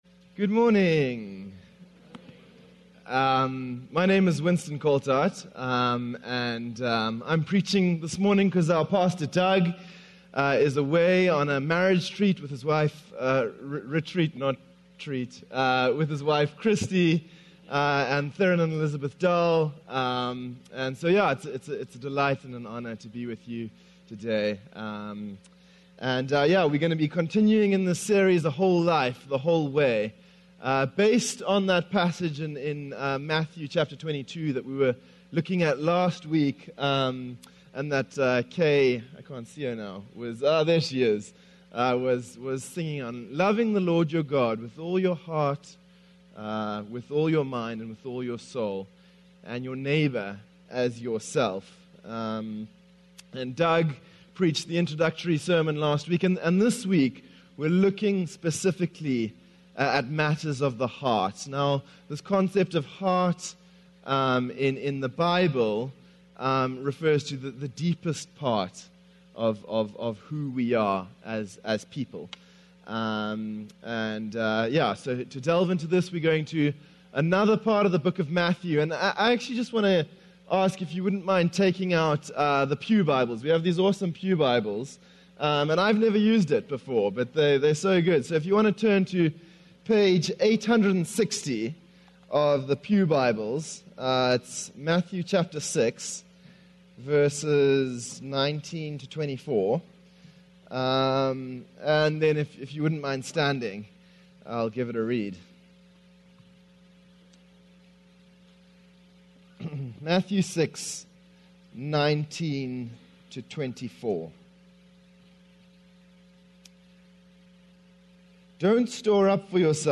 Loving God With All of Your Heart - Sermon - Woodbine